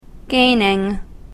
/ˈgenɪŋ(米国英語), ˈgeɪnɪŋ(英国英語)/